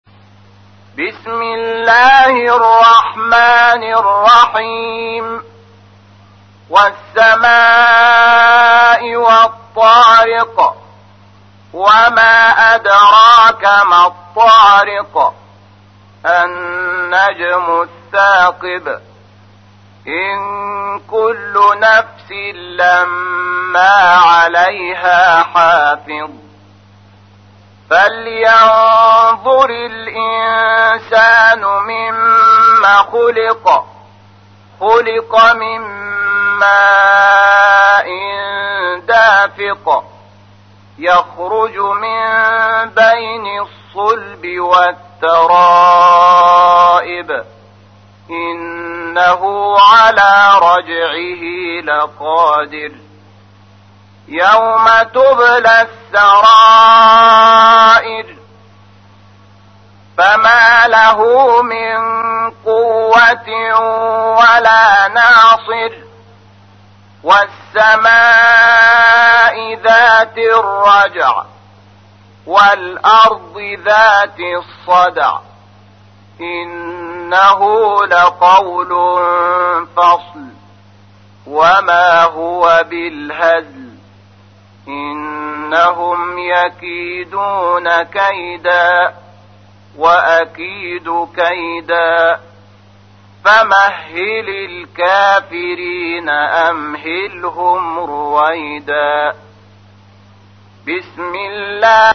تحميل : 86. سورة الطارق / القارئ شحات محمد انور / القرآن الكريم / موقع يا حسين